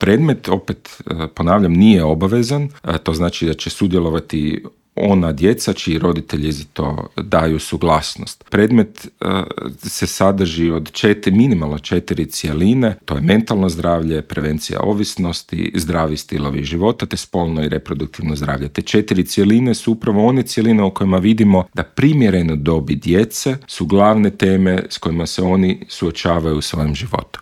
Više od 50 tisuća djece u Hrvatskoj suočava se s mentalnim teškoćama, broj djece s teškoćama u zagrebačkim osnovnim školama više se nego udvostručio u zadnjih deset godina, a više od trećine, odnosno čak 36 posto djece u Hrvatskoj je pretilo, dok je to na razini Europe slučaj s njih 25 posto, iznio je alarmantne podatke Hrvatskog zavoda za javno zdravstvo i UNICEF-a u Intervjuu Media servisa pročelnik Gradskog ureda za obrazovanje Luka Juroš i poručio: